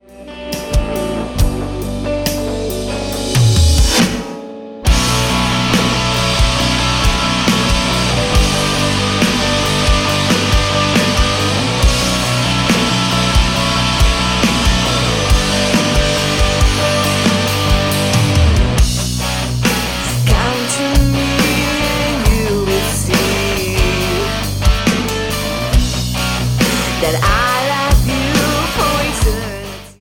11 нови песни от българската християнска рок група...